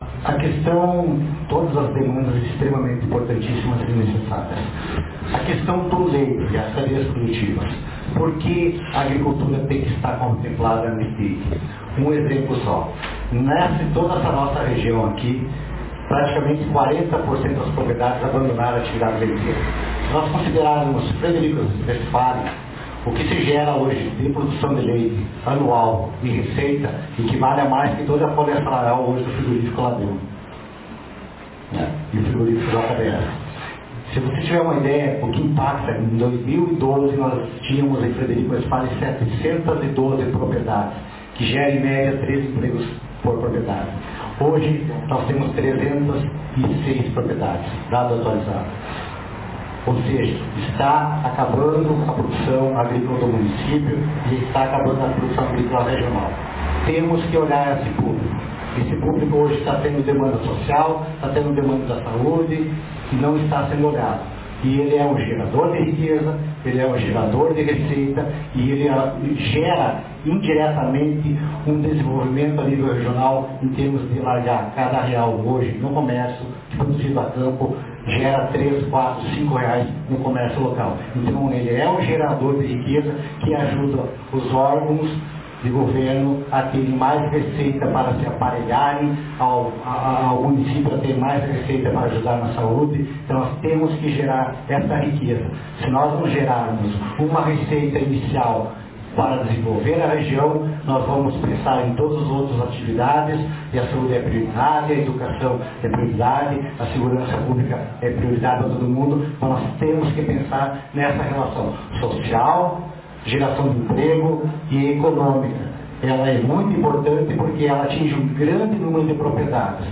Com o plenário da Câmara de Vereadores de Frederico Westphalen lotado, foram apresentadas as demandas e propostas para a utilização do recurso destinado para Consulta Popular, orçamento 2018.
O Secretário Municipal de Agricultura, Cléber Cerutti, destacou que, por mais que a saúde e segurança sejam fundamentais, é necessário que se olhe com carinho para outras demandas: